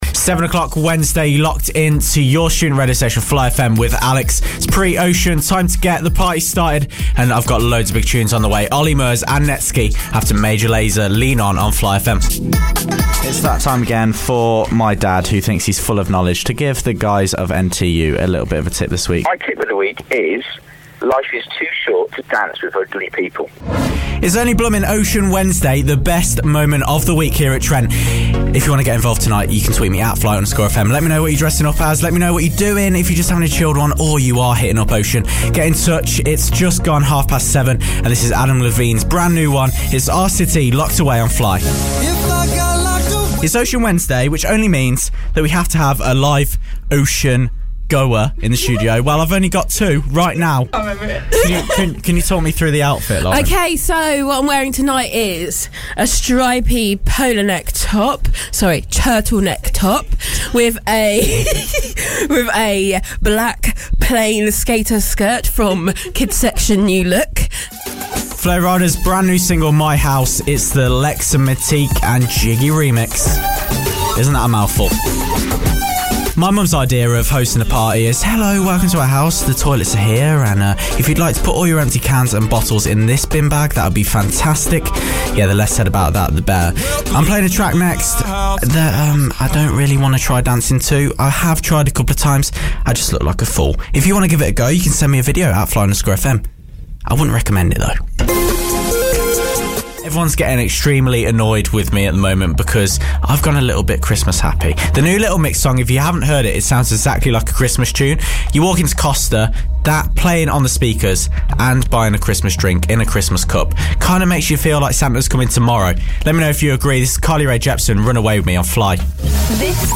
Radio Presenting Demo
Radio presenting demo 2015, shows have been Wednesday 7-9pm.